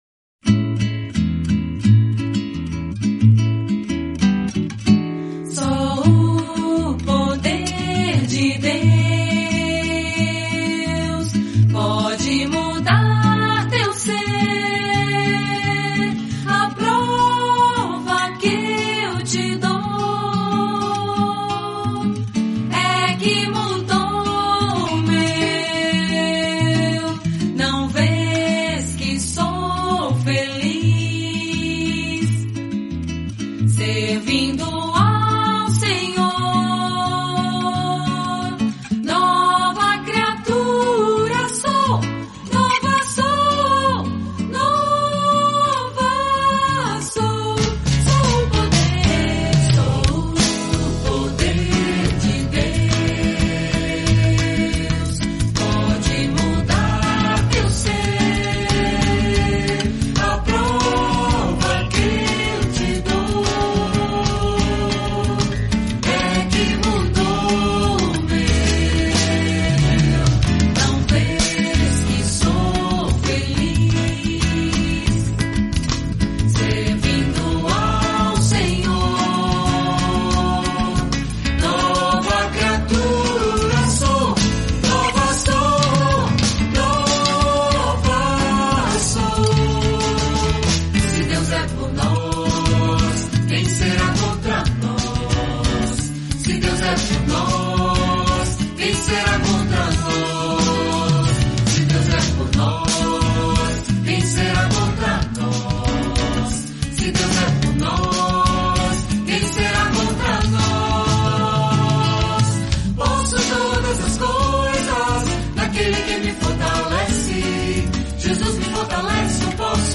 Ouça este alegre cântico no final!